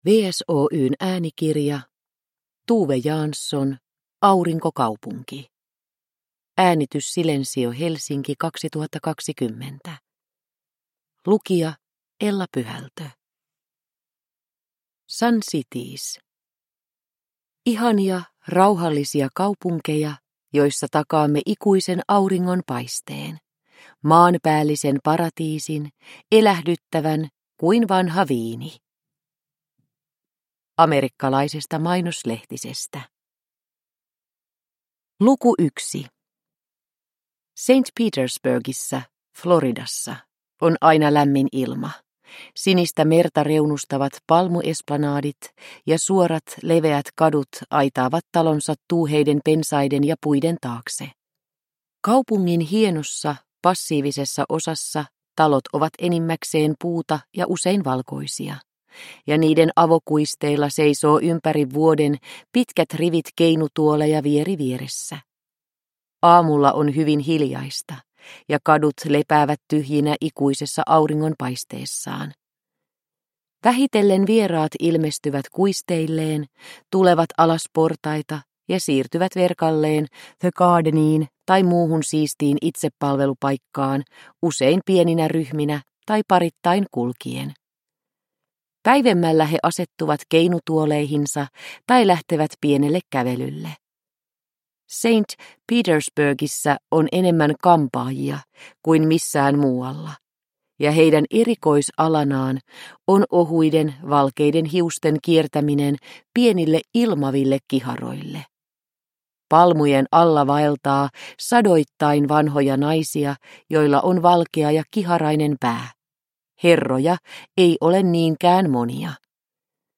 Aurinkokaupunki – Ljudbok – Laddas ner